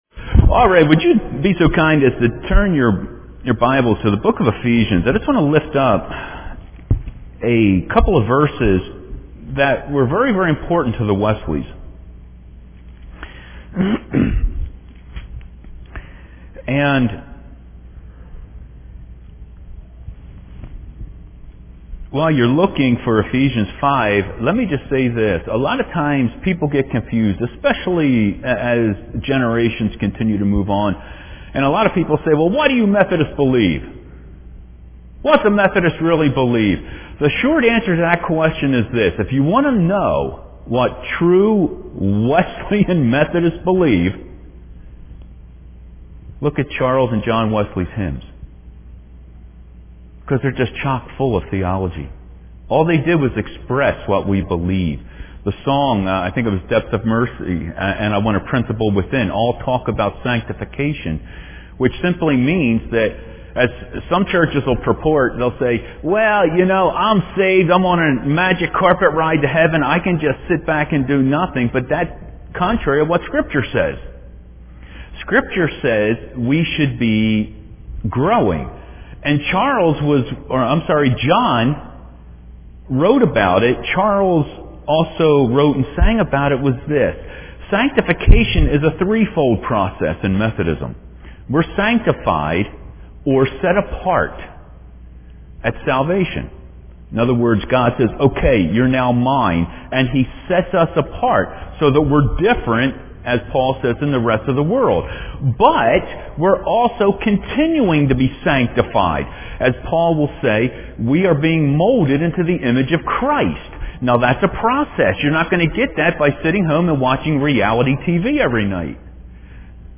Click on the following “Play” button, scroll down, and follow along (this recording is from the 9:30 service)…